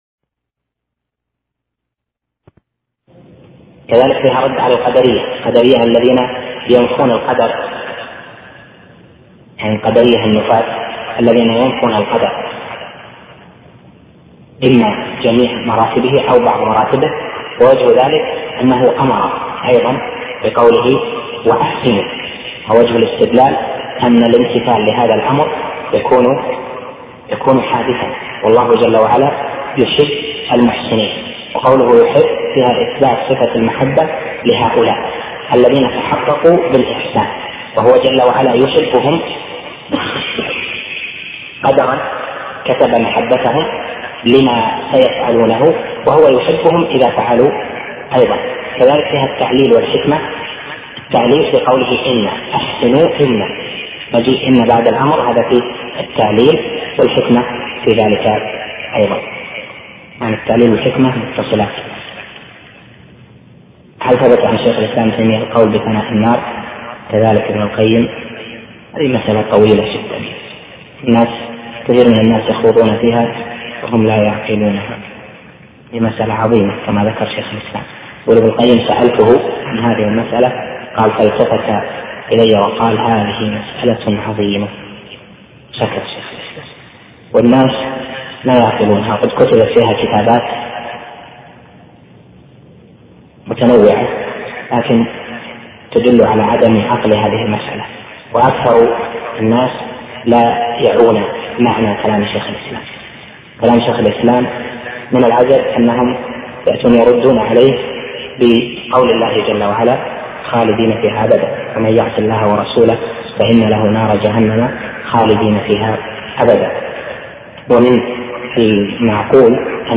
شرح العقيدة الواسطية الدرس الثامن